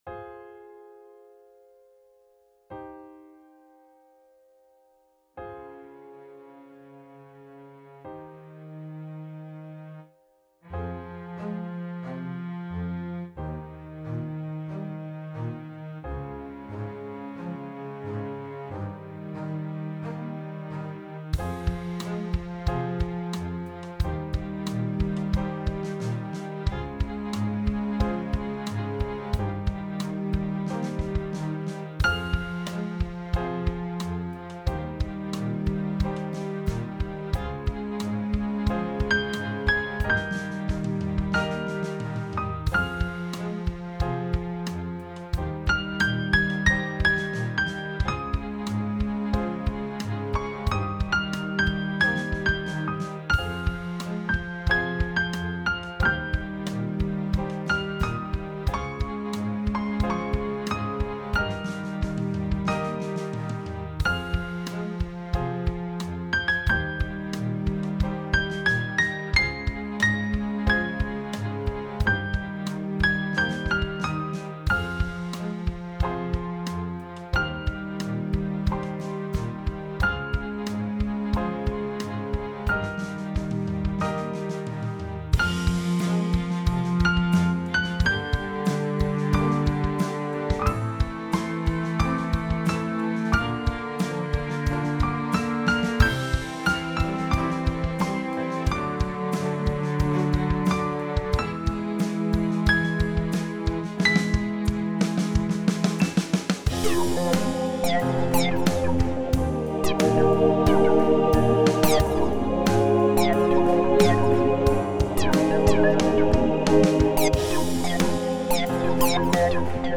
The entry is a musical piece composed for the purpose of this competition with a straightforward "storyline" in mind.